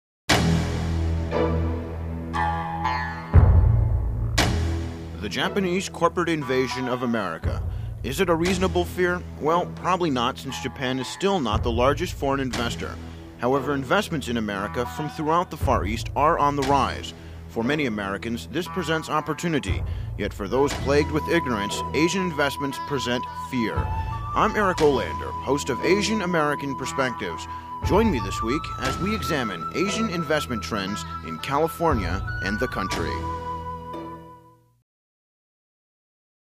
Each show was accompanied by a 30 second promotional spot that NPR affiliates could run during the week to invite listeners to tune in to Asian American Perspectives.
Asian investment in CA. promo